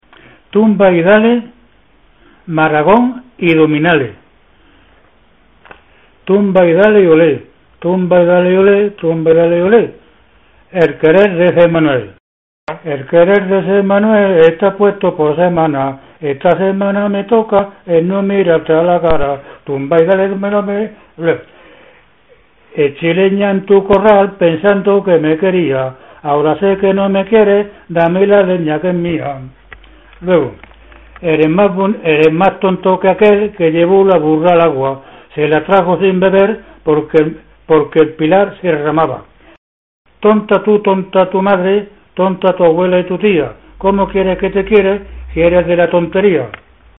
Materia / geográfico / evento: Canciones de corro Icono con lupa
Zafarraya (Granada) Icono con lupa
Secciones - Biblioteca de Voces - Cultura oral